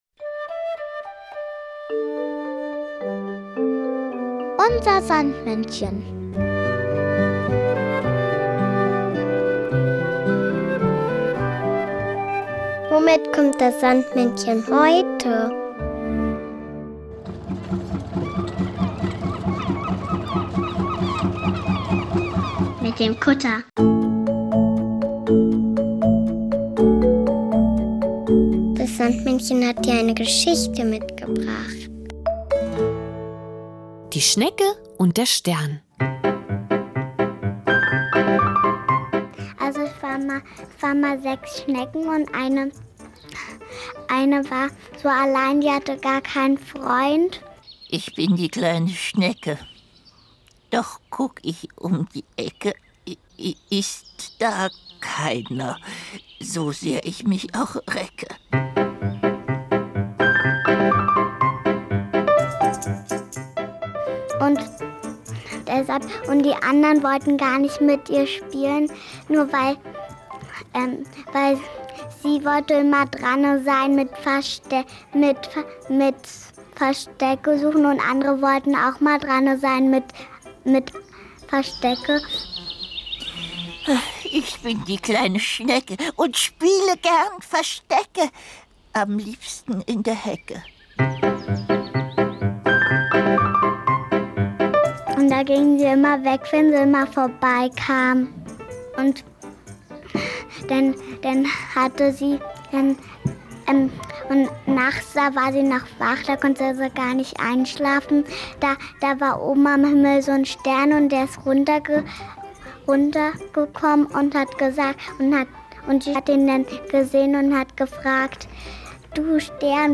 Märchen: Die Schnecke und der Stern